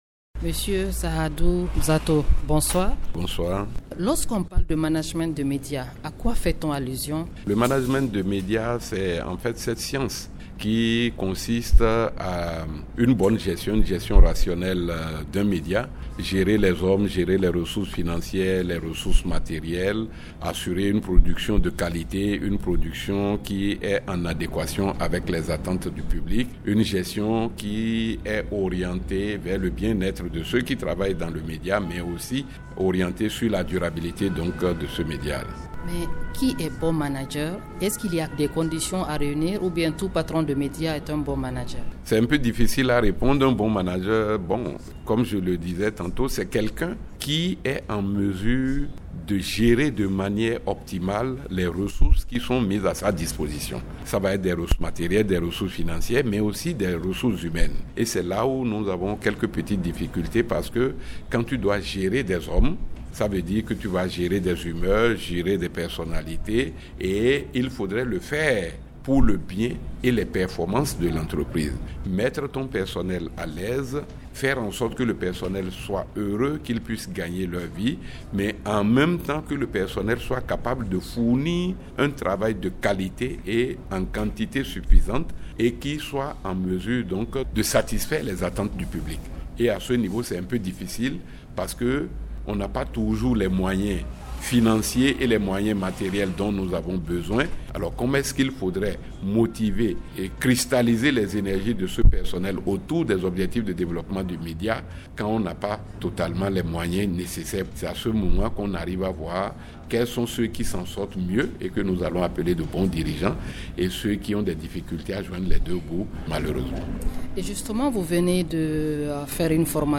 La rubrique « invité » du week-end traite aujourd’hui du « Management des médias ».
INVITE-DU-WEEK-END-MANAGEMENT-DES-MEDIAS-.mp3